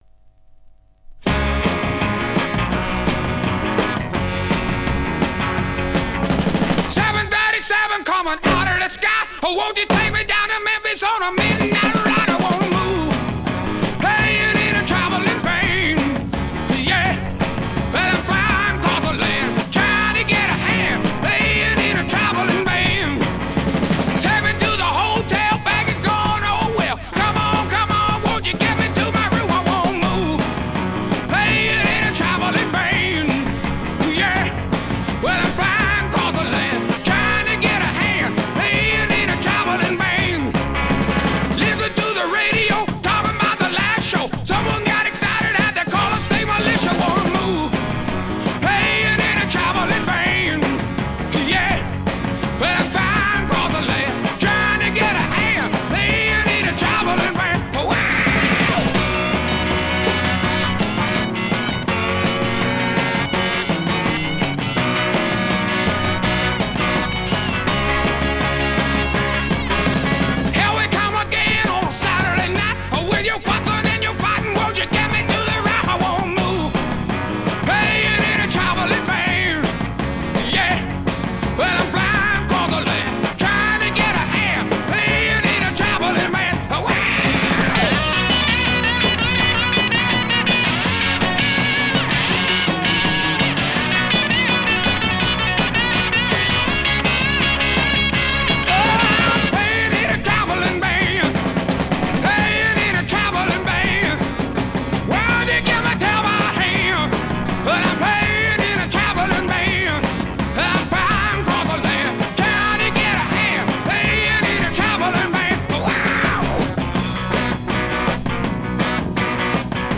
Жанр: Rock
Стиль: Classic Rock, Country Rock